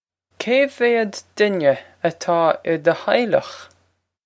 Pronunciation for how to say
Kay vay-ud din-eh a-taw ih duh h-eye-lokh?
This is an approximate phonetic pronunciation of the phrase.